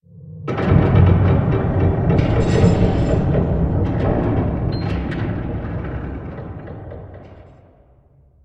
Minecraft Version Minecraft Version latest Latest Release | Latest Snapshot latest / assets / minecraft / sounds / ambient / cave / cave18.ogg Compare With Compare With Latest Release | Latest Snapshot
cave18.ogg